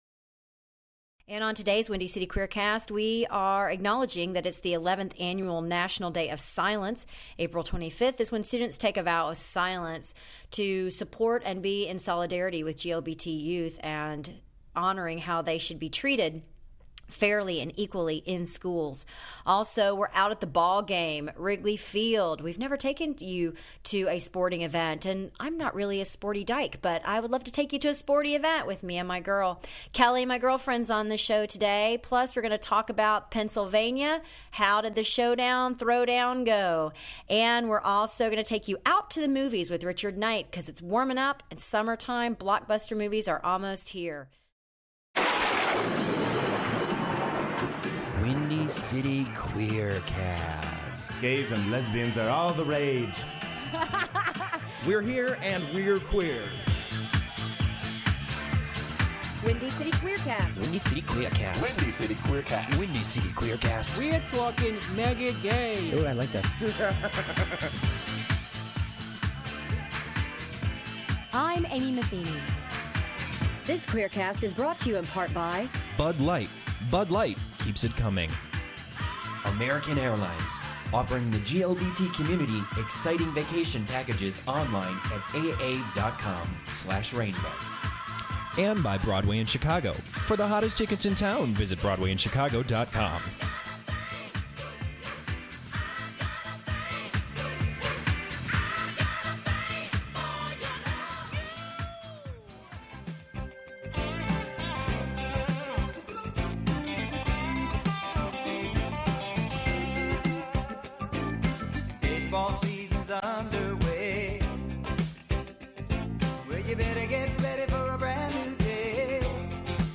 It is our first podcast ever from Wrigley Field and CUBS WIN!